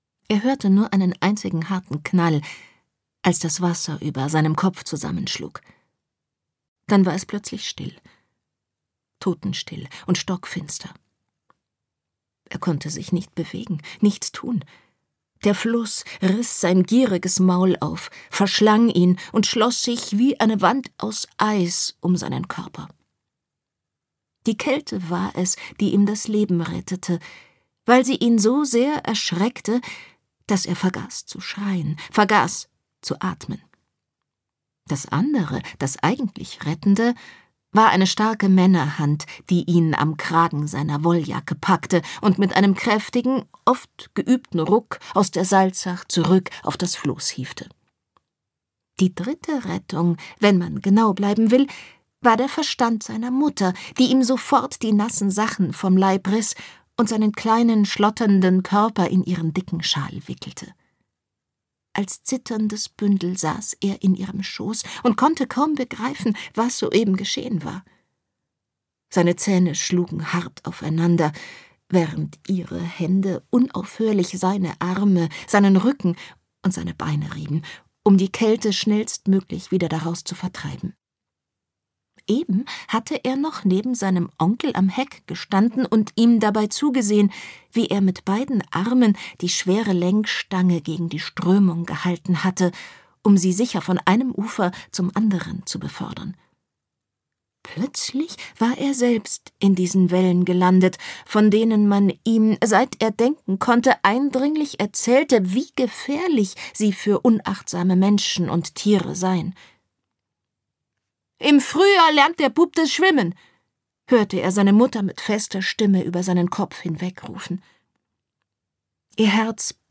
Ein spannendes Hörbuch mit Tiefgang, authentischen Figuren und sprachlicher Raffinesse:
Gekürzt Autorisierte, d.h. von Autor:innen und / oder Verlagen freigegebene, bearbeitete Fassung.